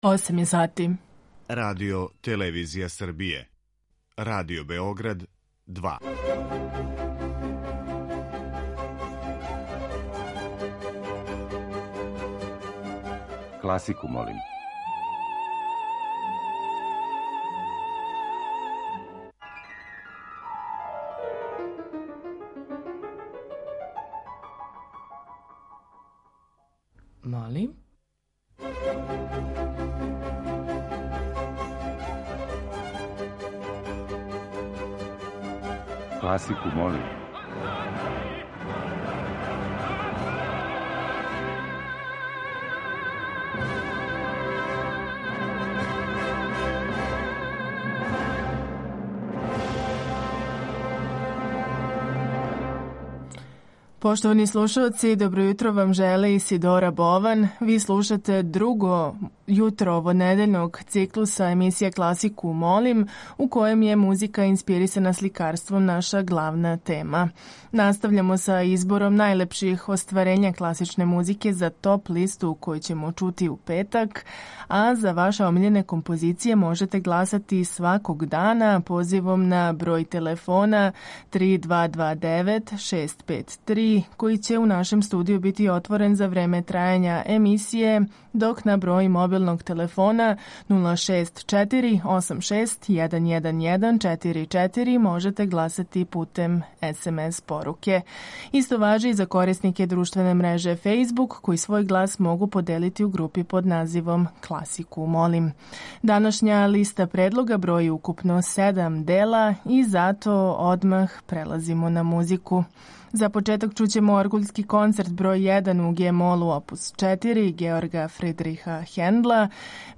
Циклус емисија Класику, молим, у којима слушаоци бирају своју омиљену музику
klasika.mp3